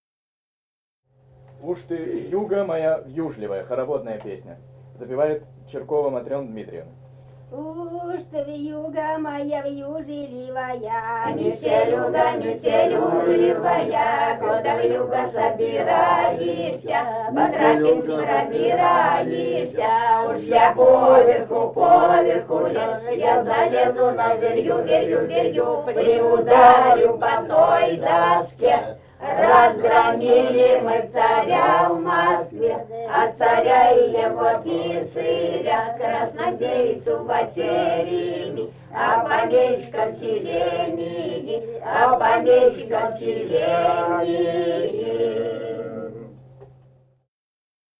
Этномузыкологические исследования и полевые материалы
Самарская область, с. Кураповка Богатовского района, 1972 г. И1318-15